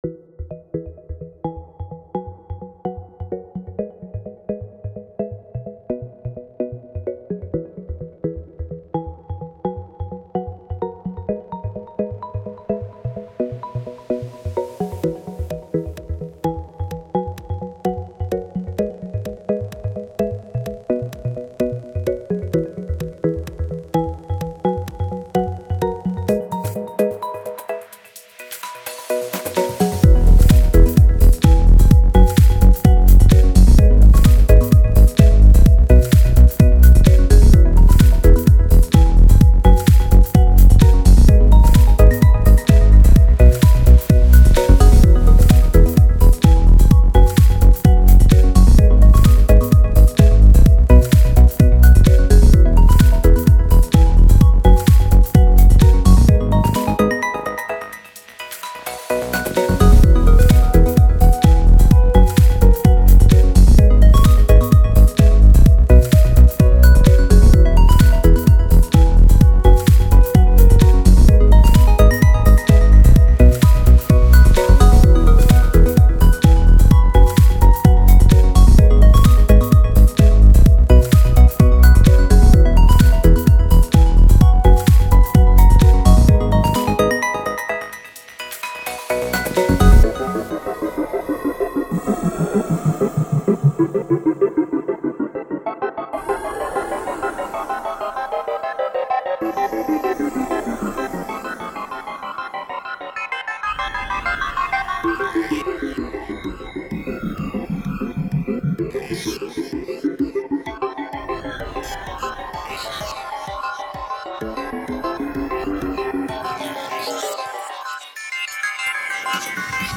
Not all is as chill as it seems... until it is.
calm song... cool
Very cool synth bass :3
House